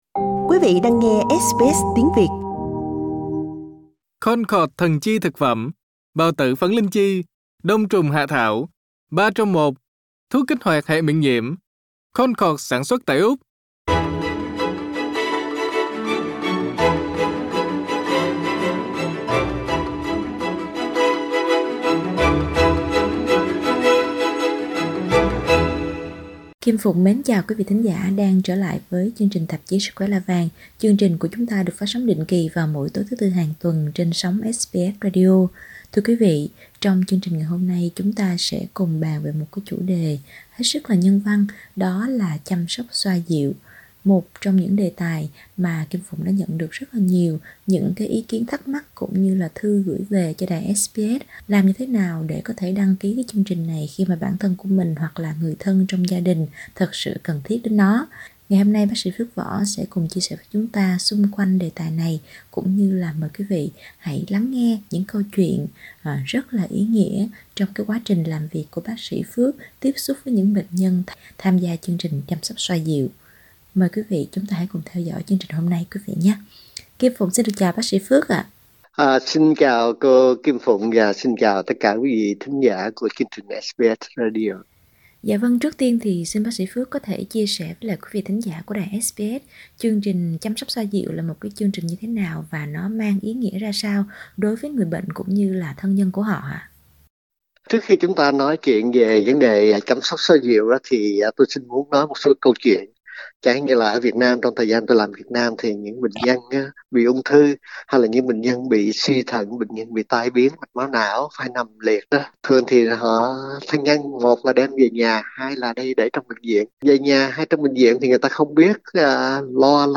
cuộc trò chuyện